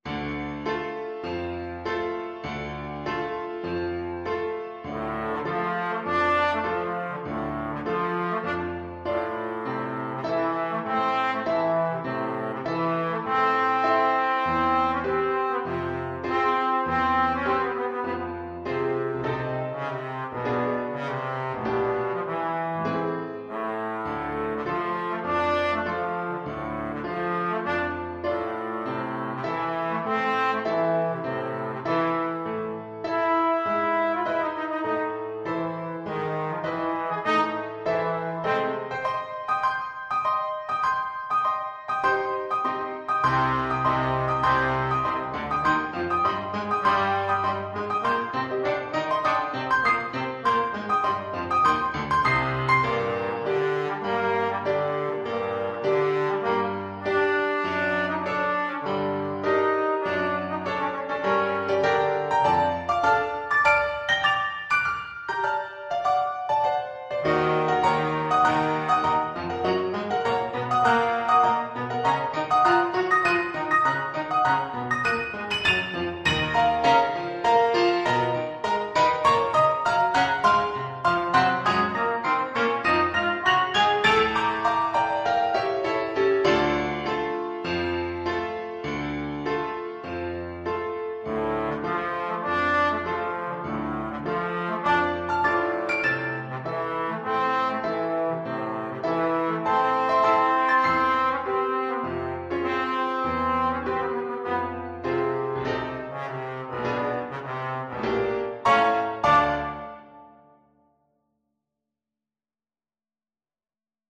G3-A5
4/4 (View more 4/4 Music)
Classical (View more Classical Trombone Music)